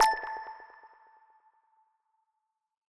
button-hover.wav